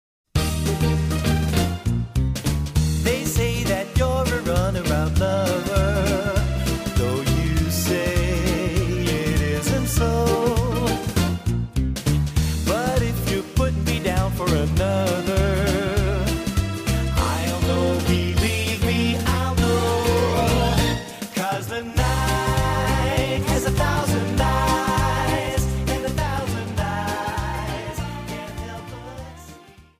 Quick Step